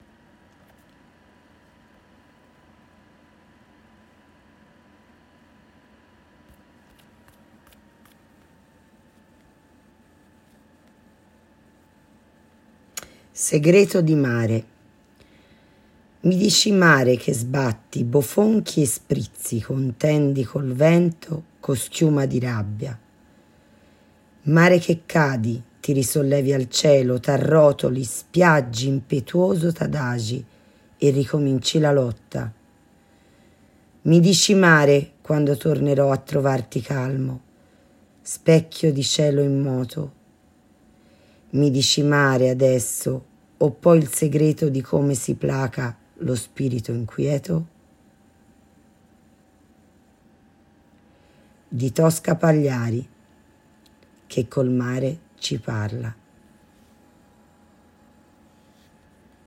Dalla voce